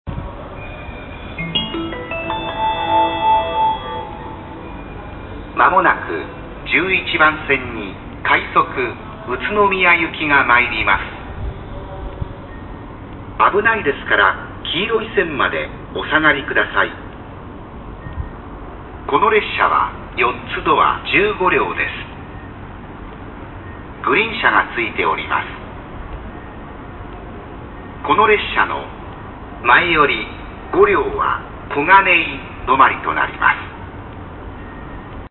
接近放送快速宇都宮行き
快速宇都宮行きの接近放送です。
切り離し放送の言い回しが「この/列車は/途中の○○で/前より○両//切り離しとなります」が「この列車の//前より○両は//○○/止まりとなります」になっています。